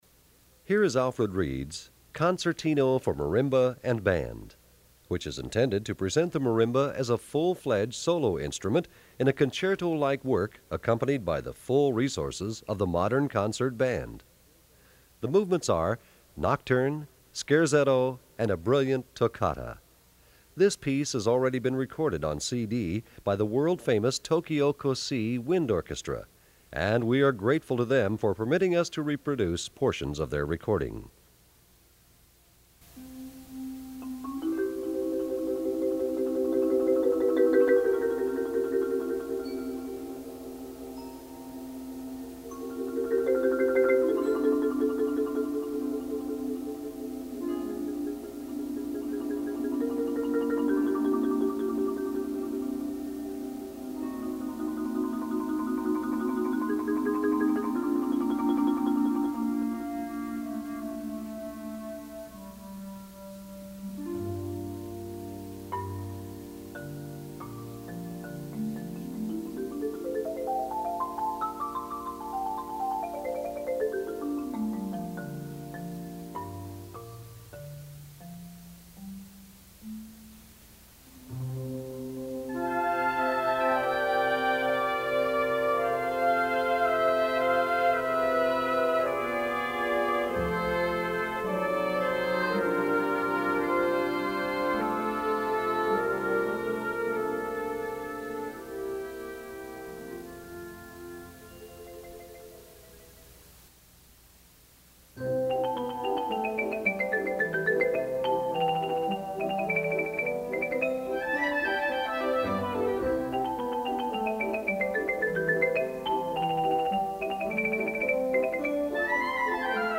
Gattung: Solostück für Marimba und Blasorchester
Besetzung: Blasorchester